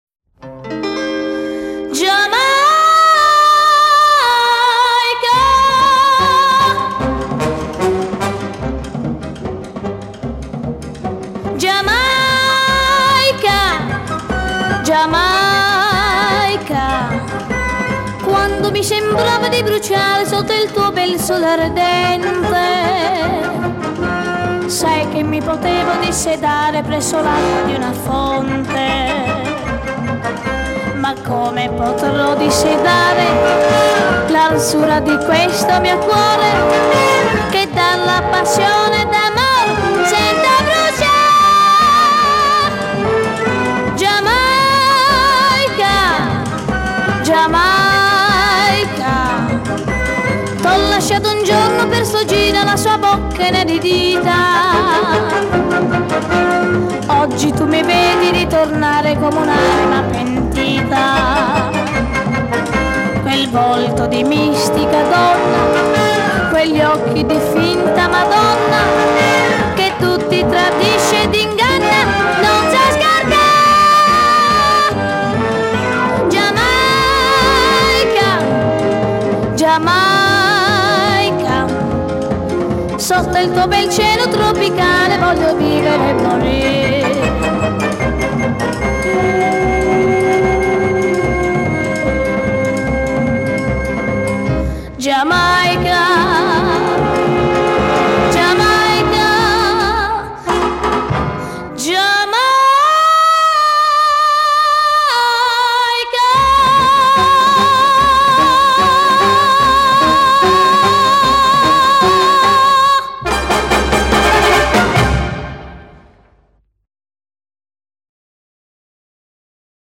纯净童音令人沉醉